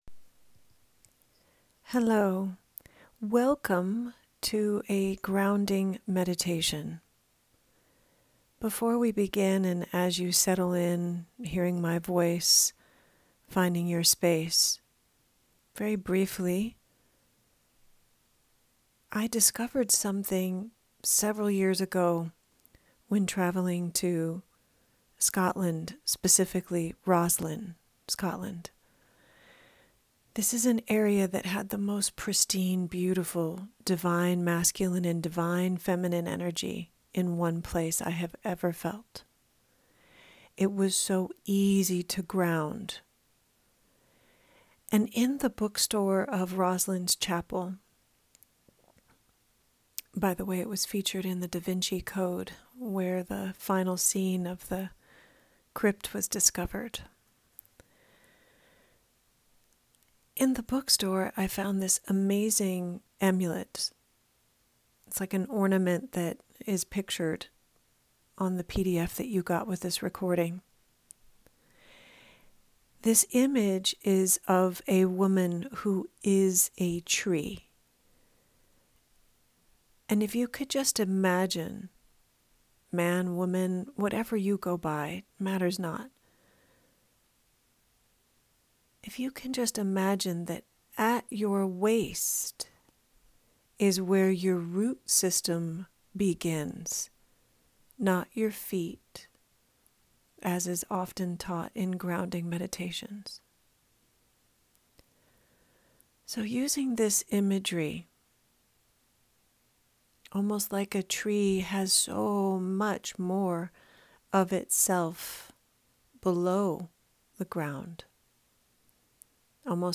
freegroundingmeditation.mp3